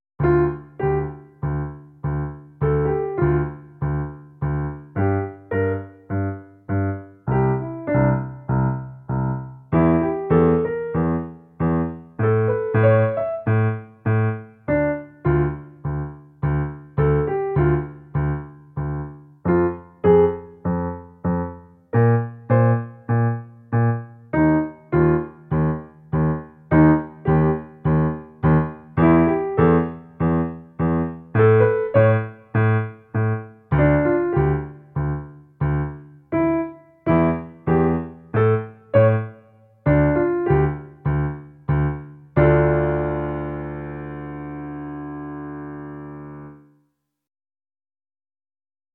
Drei Soli mit Miniskalen
Hier nun sind Aufnahmen mit dem Klavier.